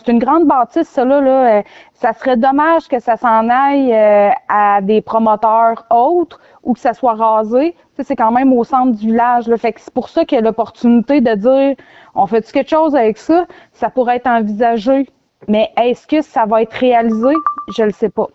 En terminant l’entrevue, Mme Gaudet a mentionné que pour se donner une idée de ce projet, ils sont allés visiter deux autres centres multifonctionnels des alentours, dont celui à Sainte-Eulalie.